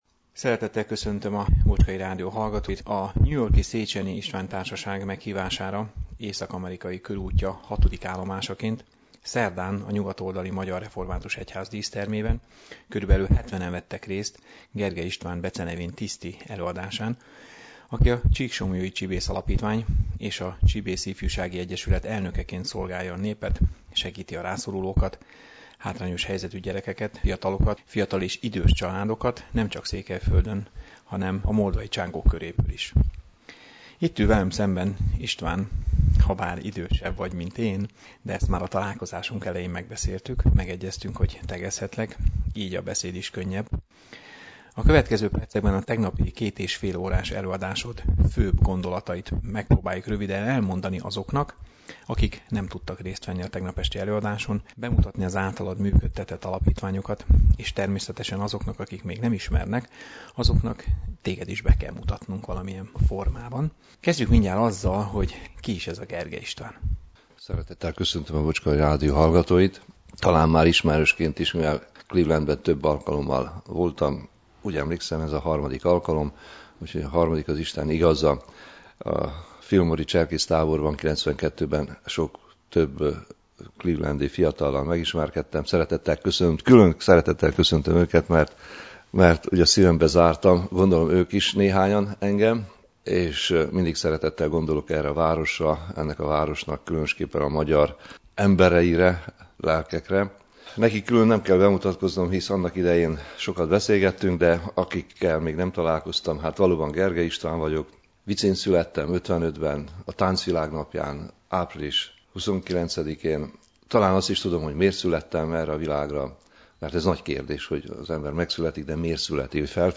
Előre elnézést kérek a hangminőségért, a készüléket túl közel tartottam a számhoz